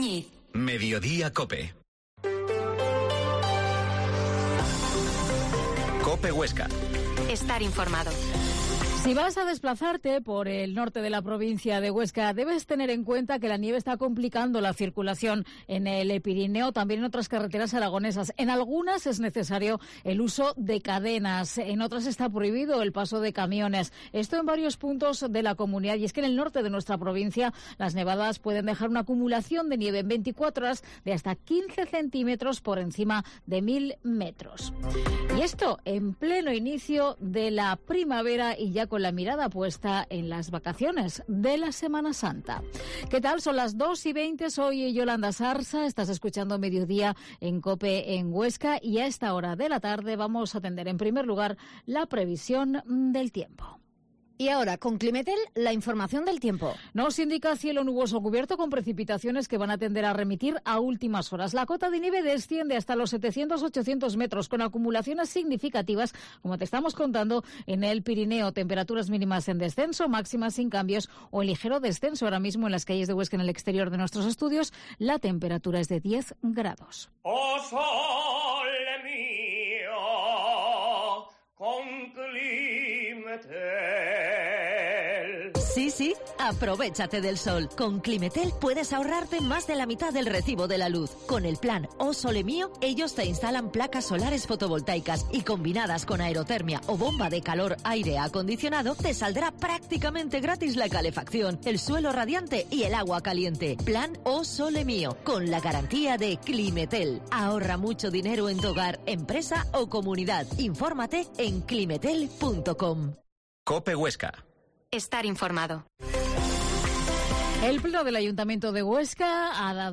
Informativo Mediodía en Huesca